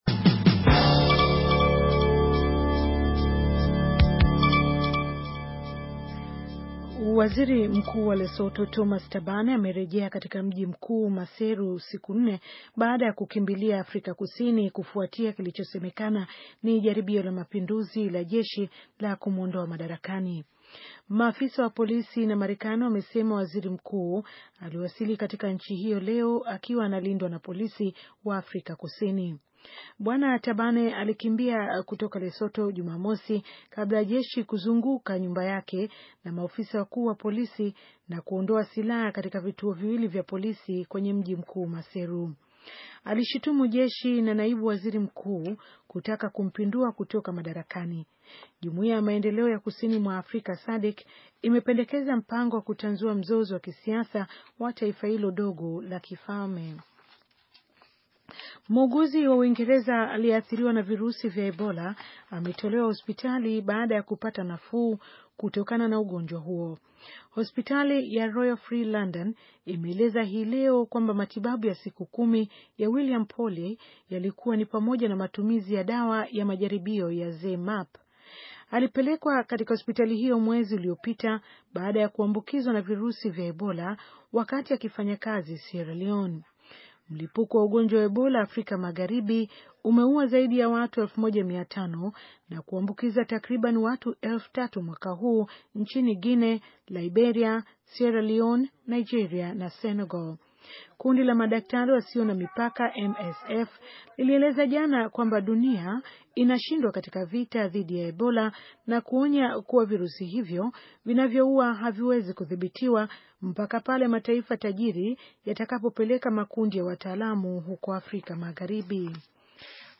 Taarifa ya habari - 6:44